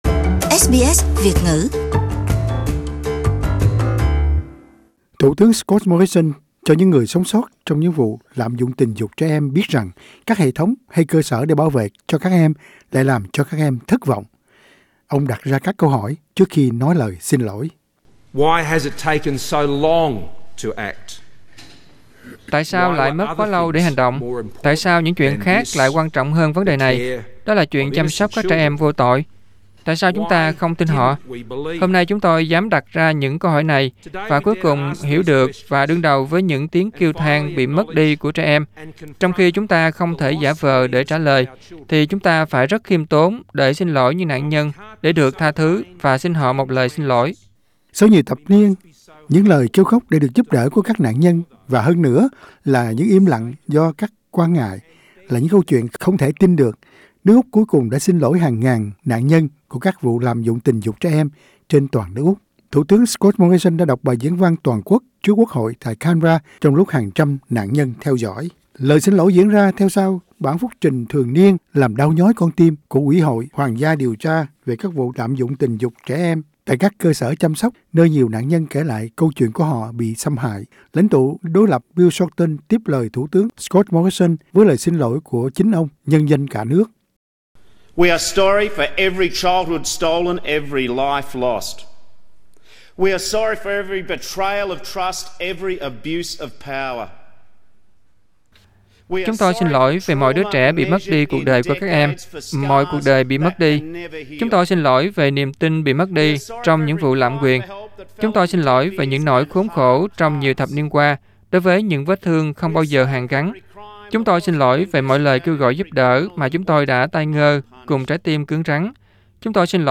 Thủ tướng Scott Morrison đọc bài diễn văn xin lỗi trước hàng ngàn nạn nhân của vụ xâm hại tình dục trẻ em.